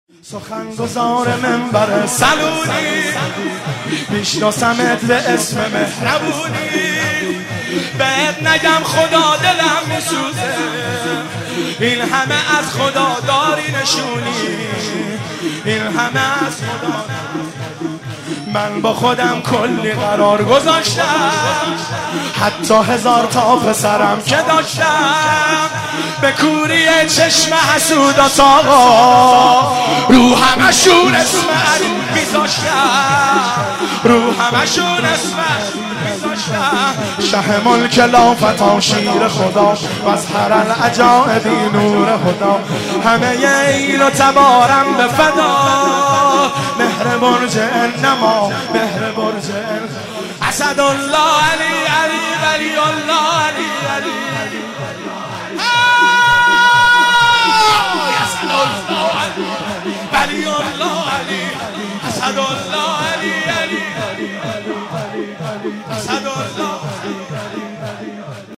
سرود عید سعید غدیر خم 1392